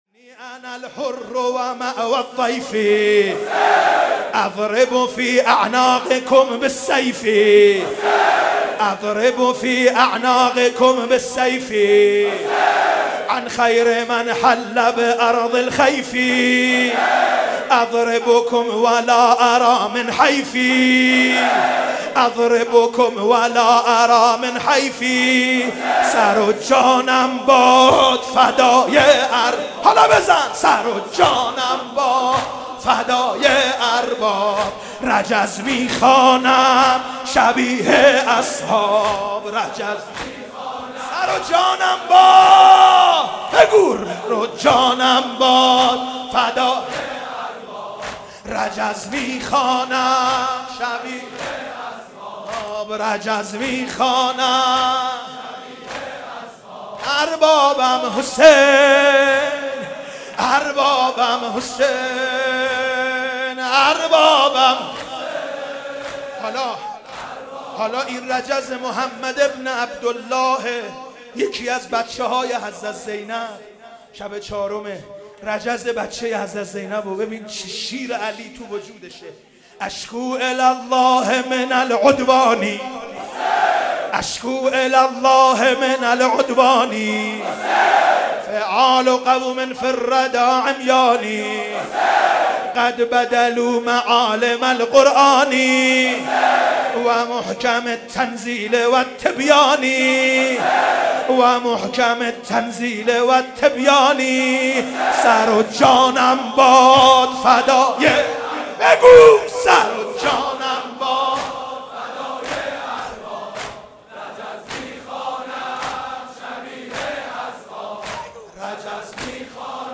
محرم 94